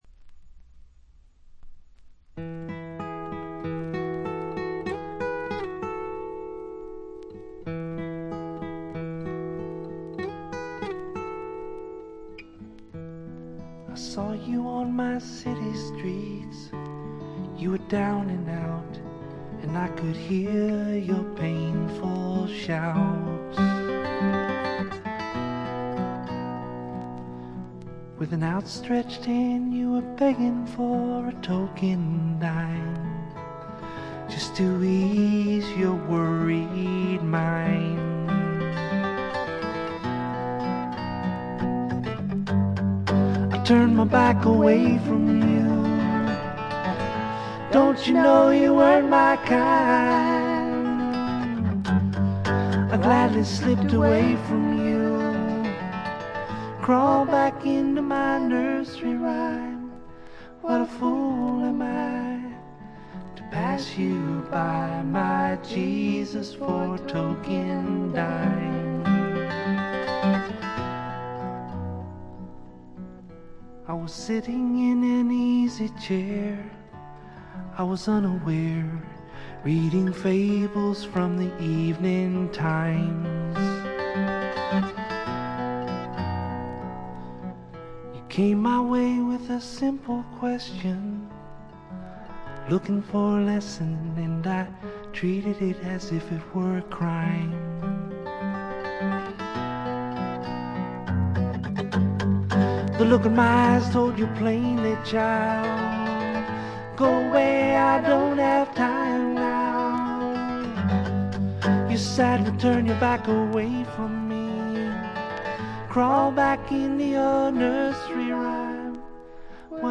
バックグラウンドノイズ、チリプチやや多めですが鑑賞を妨げるようなものはありません。
試聴曲は現品からの取り込み音源です。